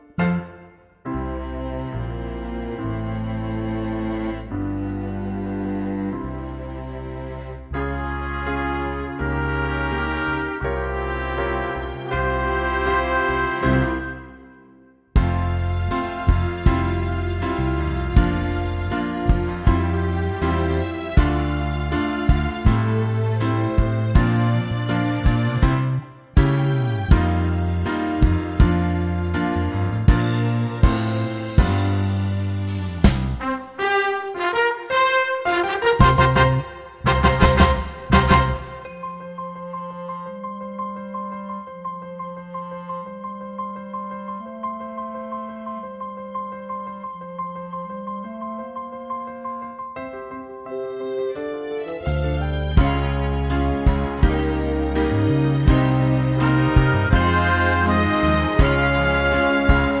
no Backing Vocals Musicals 4:42 Buy £1.50